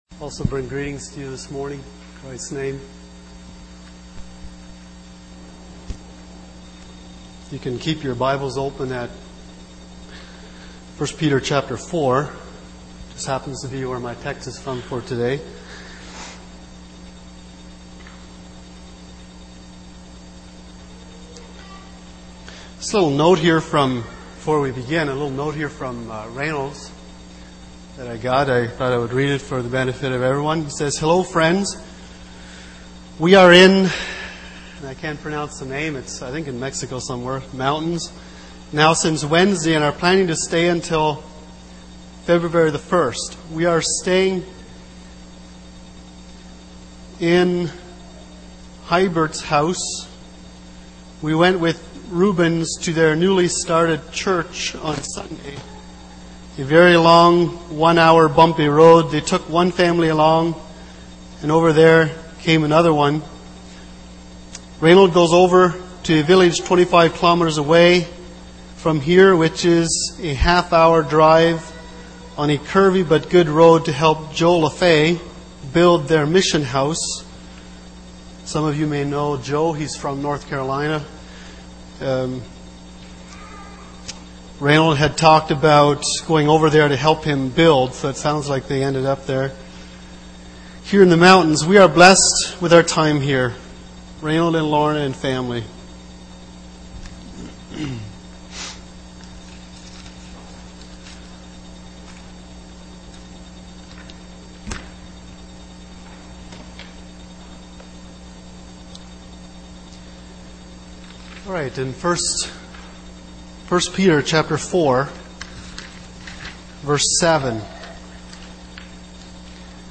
Sunday Morning Sermon Passage: 1 Peter 4:7-11